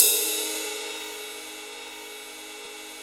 Index of /90_sSampleCDs/Roland L-CD701/CYM_Rides 1/CYM_Ride menu
CYM 18  RD1.wav